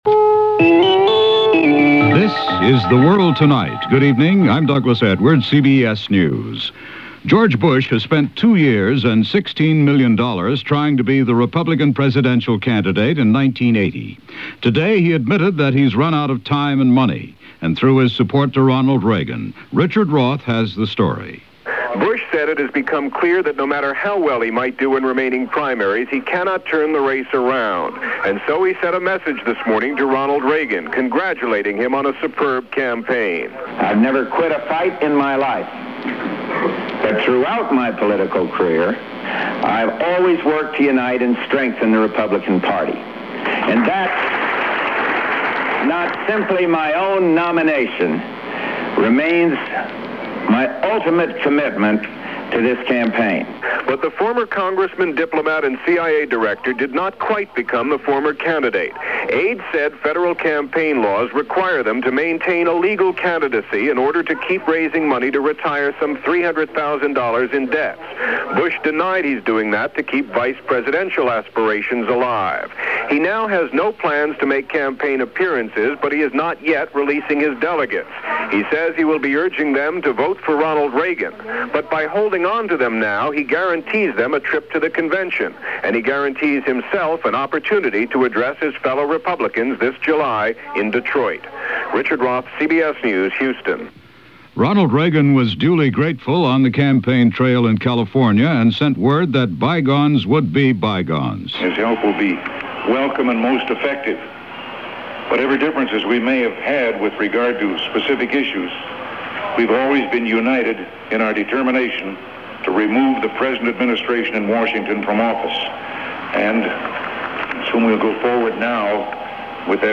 And that’s a small chunk of what happened on this day 35 years ago, as presented by CBS Radio’s The World Tonight on May 26, 1980.